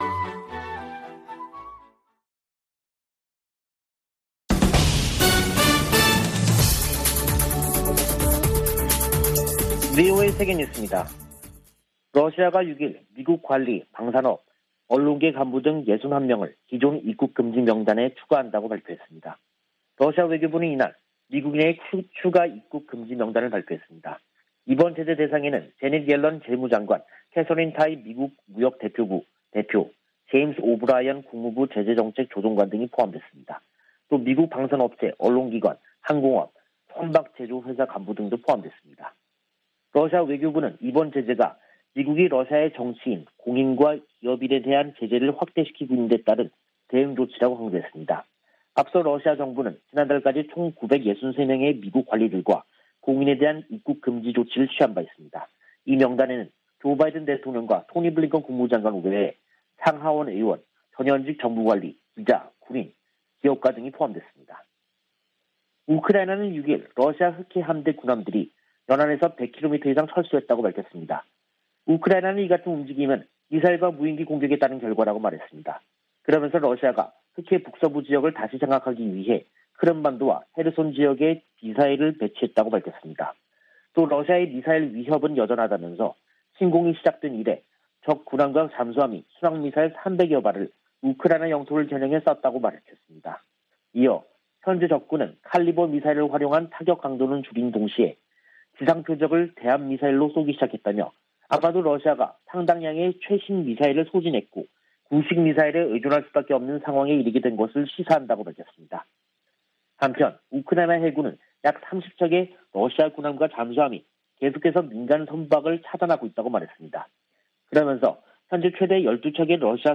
VOA 한국어 간판 뉴스 프로그램 '뉴스 투데이', 2022년 6월 7일 2부 방송입니다. 북한이 7차 핵실험을 감행할 경우 미국과 한국은 신속하고 강력한 대응을 할 것이라고 서울을 방문중인 웬디 셔먼 미국 국무부 부장관이 경고했습니다. 국제원자력기구는 북한 풍계리에서 핵실험을 준비 징후를 포착했다고 밝혔습니다. 미국의 전문가들은 북한이 최근 8발의 단거리탄도미사일을 발사한 것은 전시 한국에 기습 역량을 과시하려는 것이라고 분석했습니다.